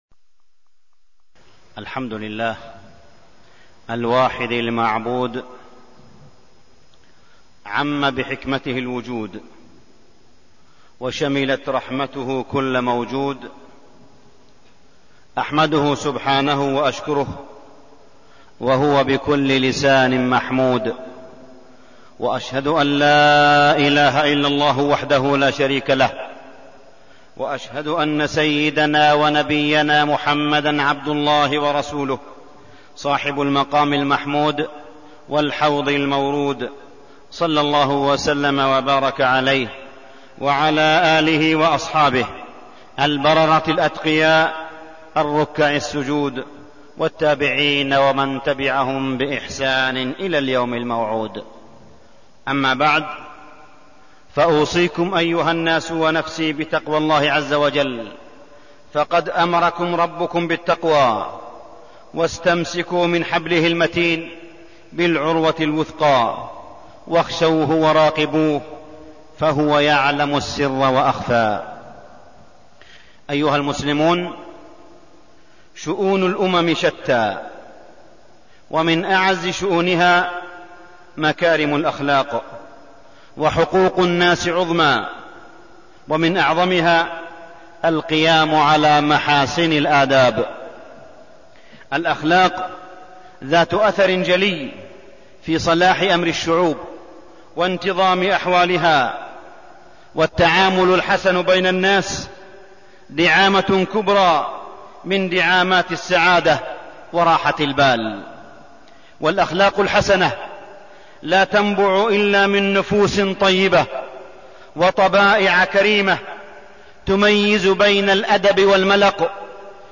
تاريخ النشر ٢٦ صفر ١٤١٧ هـ المكان: المسجد الحرام الشيخ: معالي الشيخ أ.د. صالح بن عبدالله بن حميد معالي الشيخ أ.د. صالح بن عبدالله بن حميد مكارم الأخلاق